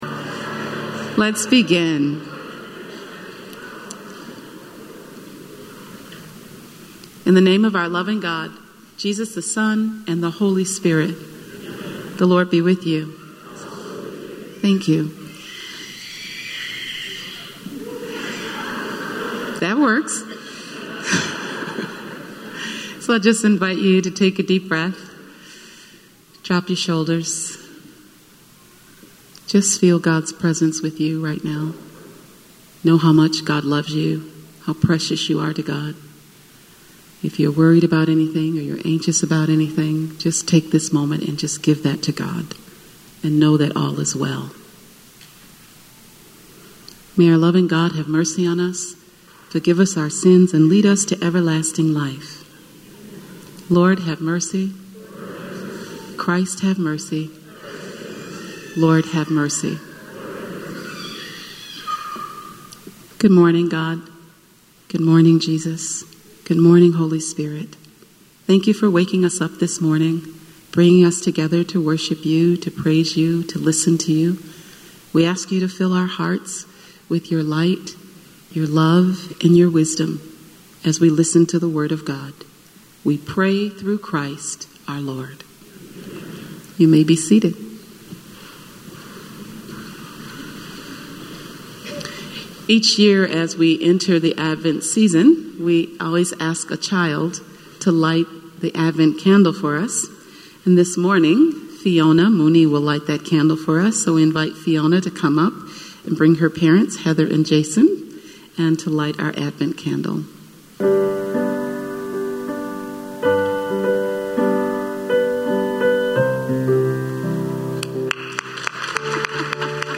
Spiritus Christi Mass December 2nd, 2018